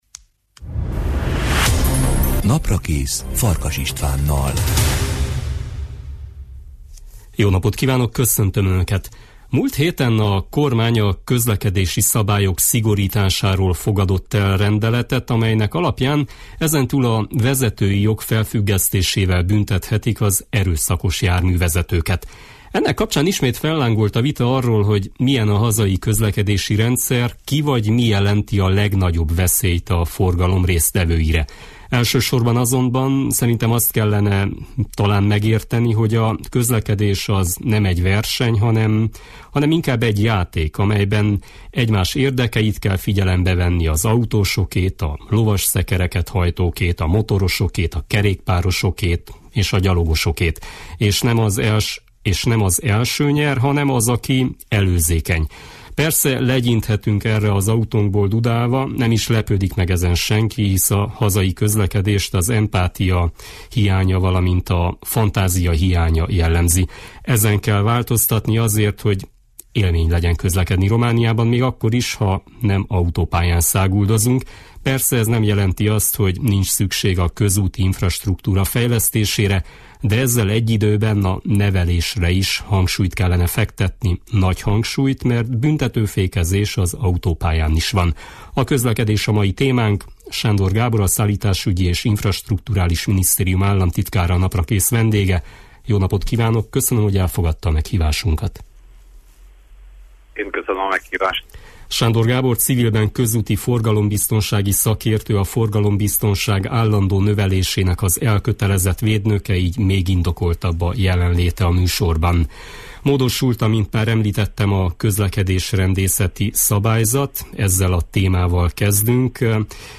Közlekedésbiztonság: Sándor Gábor államtitkárral beszélgettünk